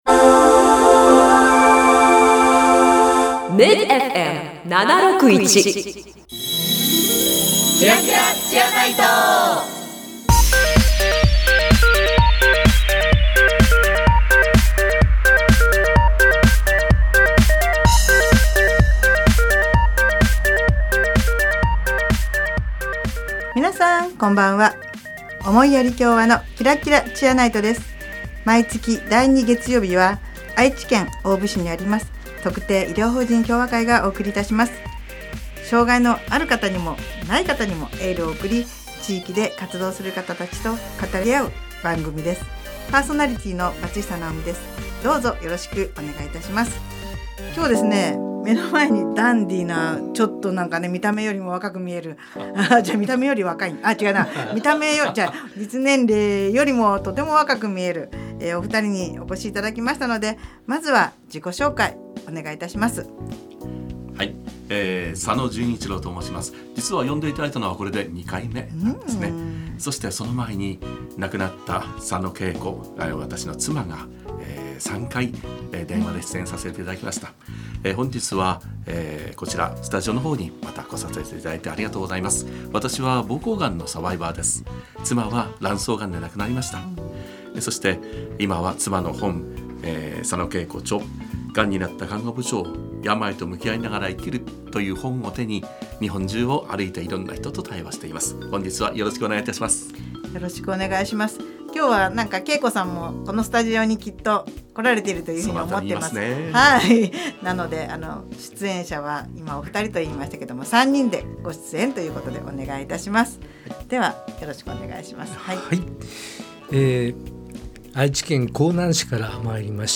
【放送時間 】第2月曜日 19：00 MID-FM 76.1 【ゲスト】
この番組では、地域の医療・福祉に携わる方々と語り合い、偏見にさらされやすい障がいのある方に心からのエールを送ります。 毎回、医療・福祉の現場に直接携わる方などをゲストに迎え、現場での色々な取り組みや将来の夢なども語り合います。